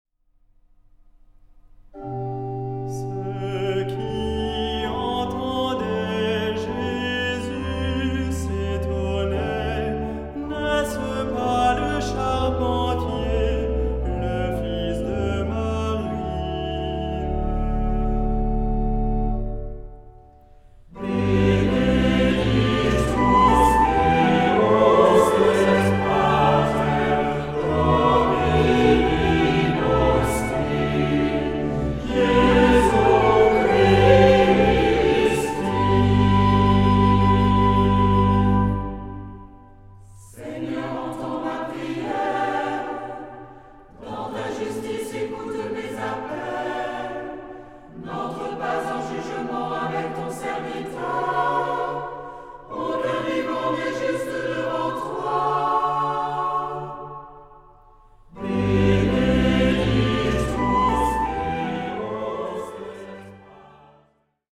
Genre-Style-Form: troparium ; Psalmody
Mood of the piece: collected
Type of Choir: SAH  (3 mixed voices )
Instruments: Organ (1) ; Melody instrument (1)
Tonality: G major